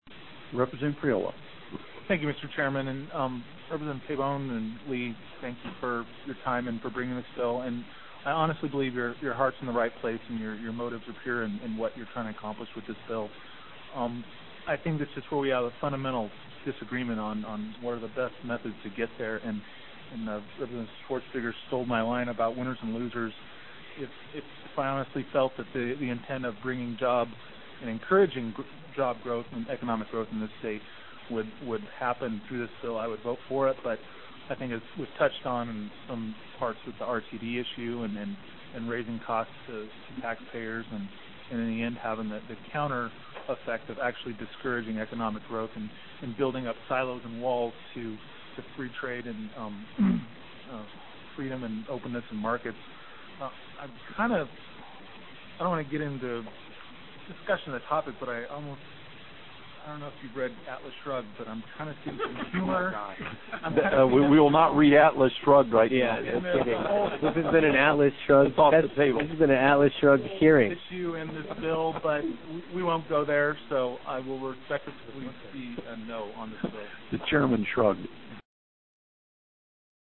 If you’re looking for a good reason why this latest bill was killed by Republicans on the House Finance Committee, though, we’re sorry to tell you you won’t find it in the audio of the hearing. Go ahead and try to figure out what Rep. Kevin Priola’s objection was from this audio clip: